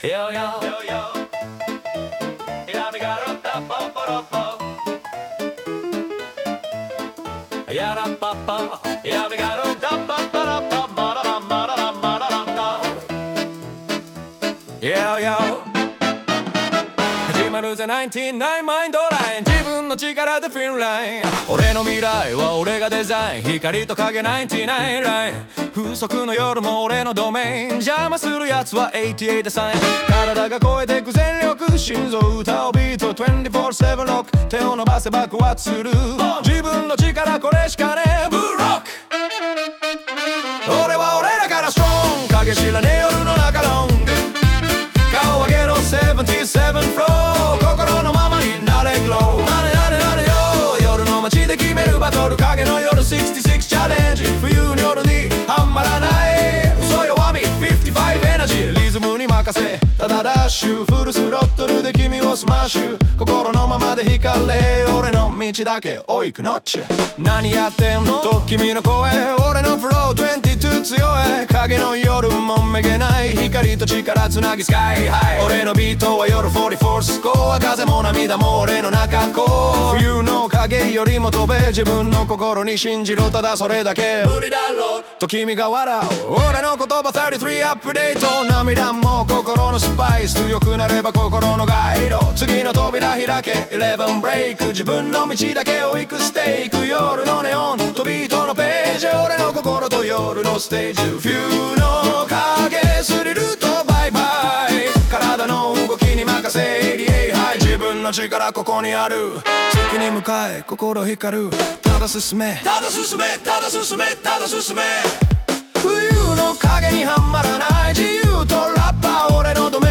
イメージ：スウィング・ジャズ,ハウス,男性ボーカル,1920年代ホーン